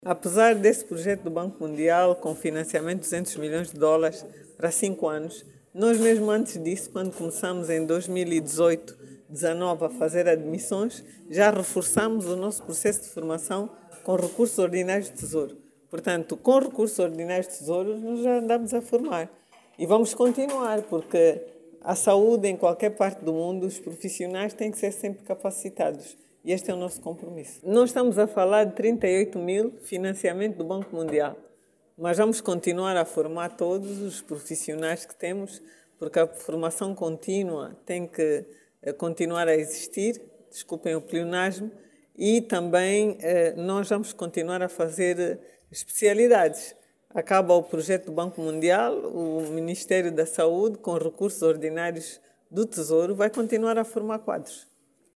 A Ministra da Saúde Sílvia Lutucuta assegurou durante a cerimónia de despedida dos bolseiros que o sector, vai continuar a formar quadro de especialidade para melhorar o atendimento nos bancos de urgências nas distintas unidades hospitalares do país.
SILVIA-LUTUKUTA-07-HR.mp3